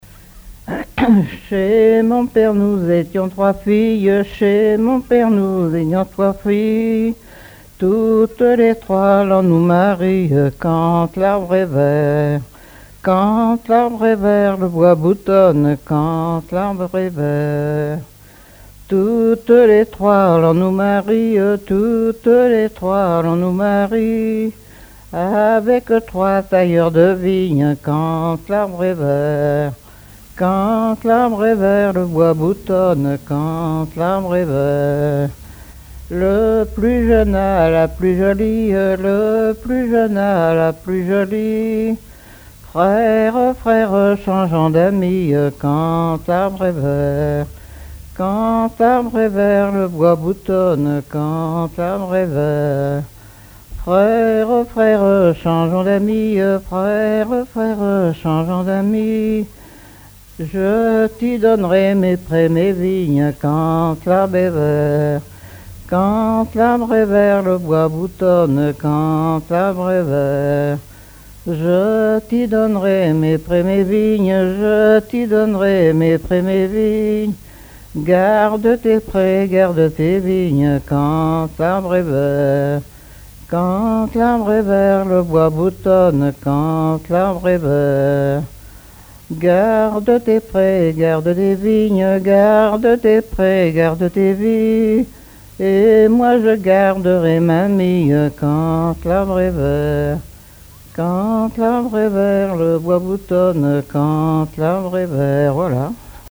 danse : ronde : demi-tour
Genre laisse
collecte en Vendée
Pièce musicale inédite